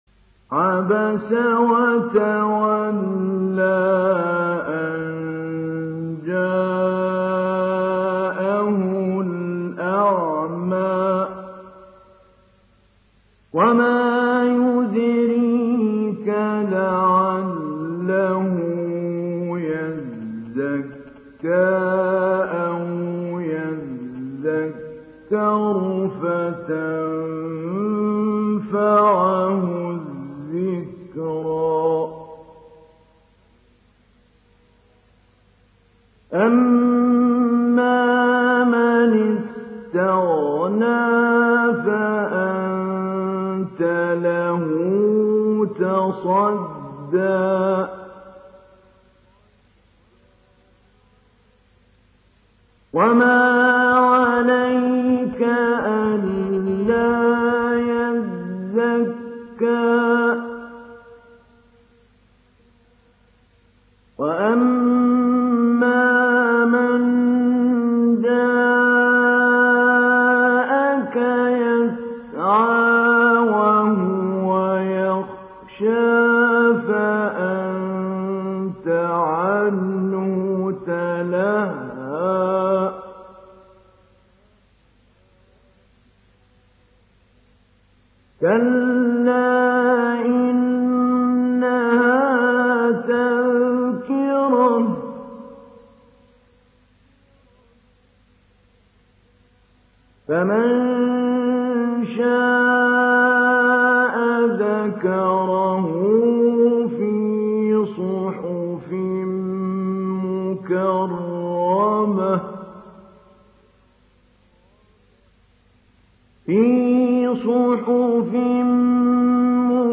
دانلود سوره عبس mp3 محمود علي البنا مجود روایت حفص از عاصم, قرآن را دانلود کنید و گوش کن mp3 ، لینک مستقیم کامل
دانلود سوره عبس محمود علي البنا مجود